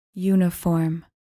Pronounced: YOU-nee-form